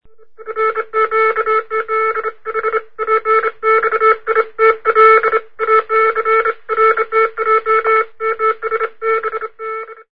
CODIGOMORSE.mp3